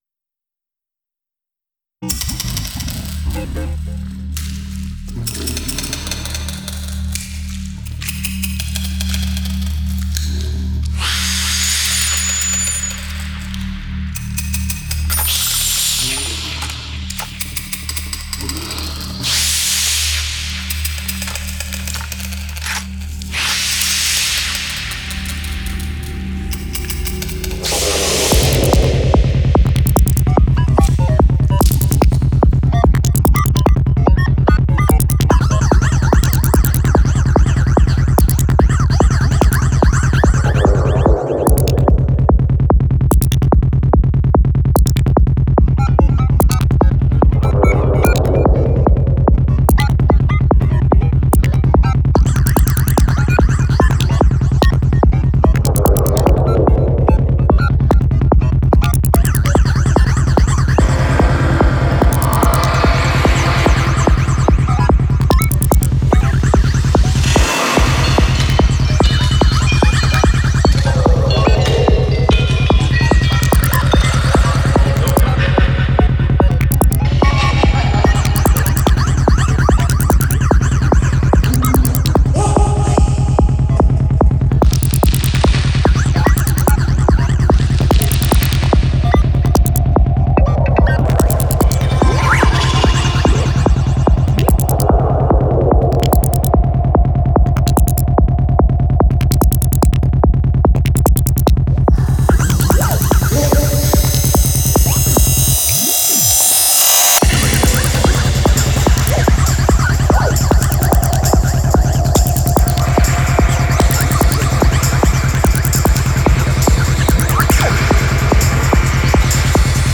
Style: Goa Trance / Dark Psytrance